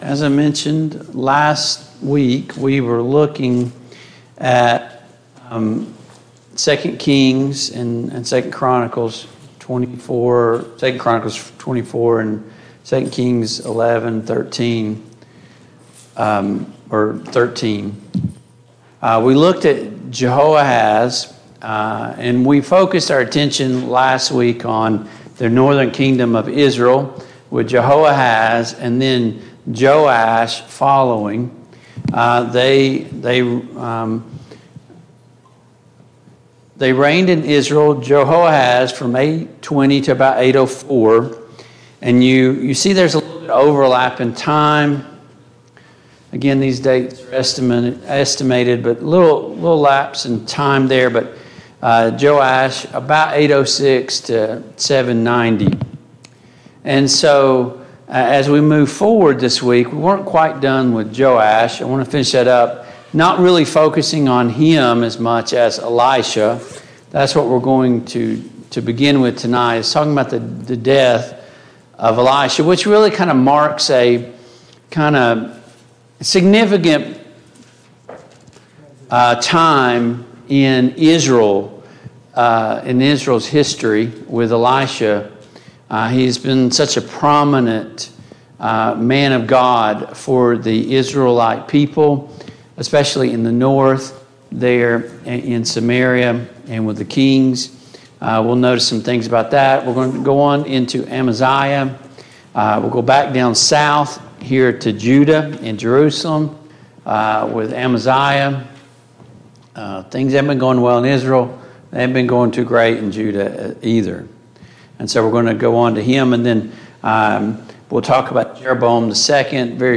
The Kings of Israel and Judah Passage: 2 Kings 14, 2 Chronicles 25 Service Type: Mid-Week Bible Study Download Files Notes « 3.